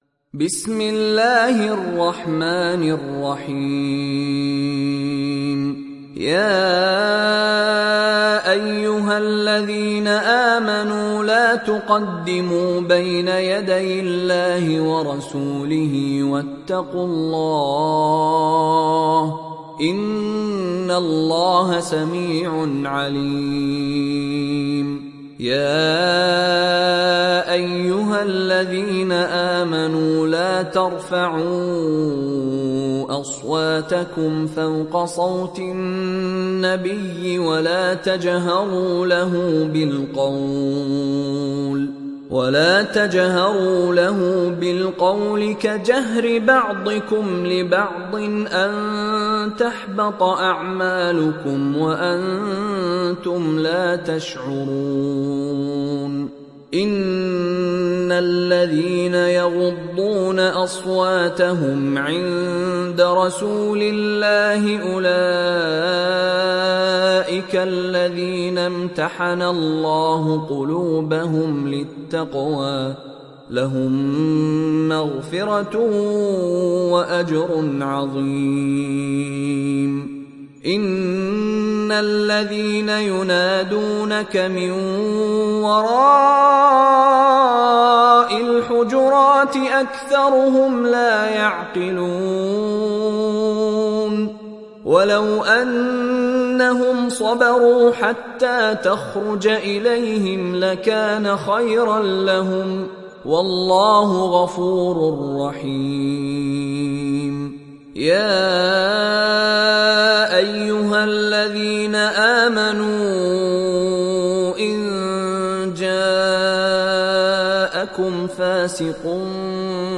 Hucurat Suresi İndir mp3 Mishary Rashid Alafasy Riwayat Hafs an Asim, Kurani indirin ve mp3 tam doğrudan bağlantılar dinle